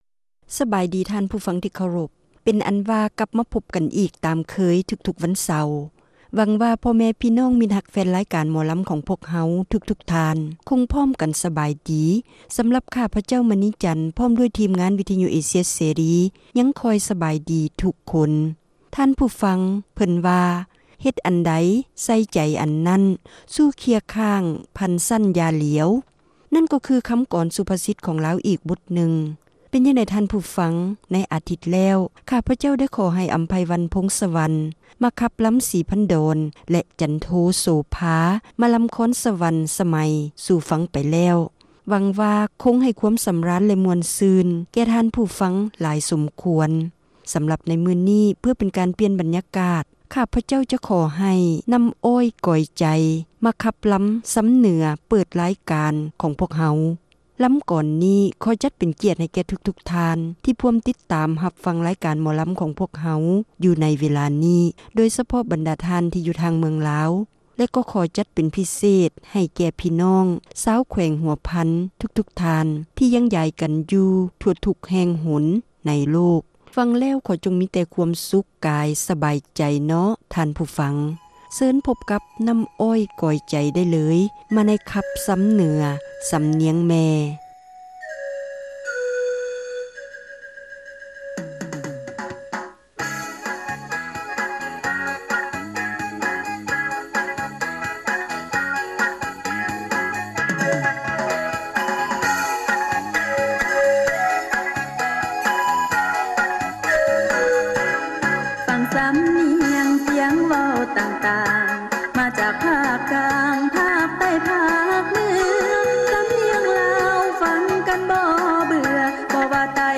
ຣາຍການໜໍລຳ ປະຈຳສັປະດາ ວັນທີ 7 ເດືອນ ທັນວາ ປີ 2007